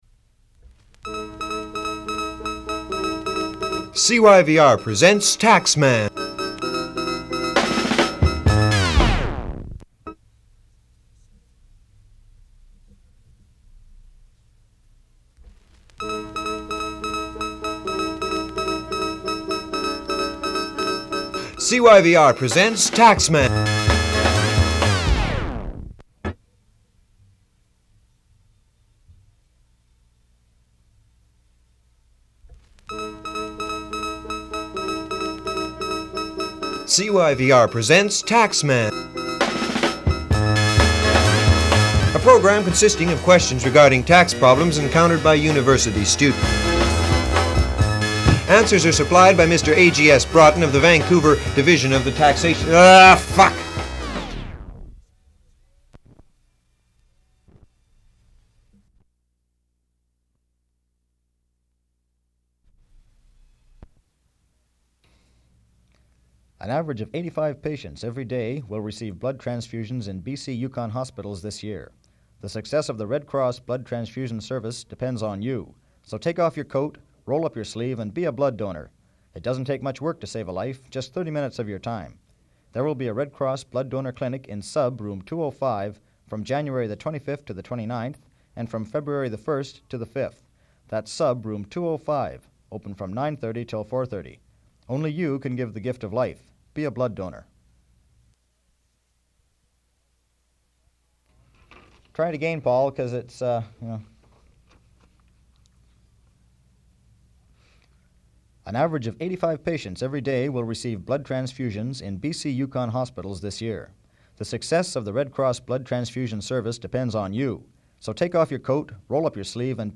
[Production outtakes]
Recording of outtakes from various advertisements, promotional spots and public service announcements to be broadcast on CYVR.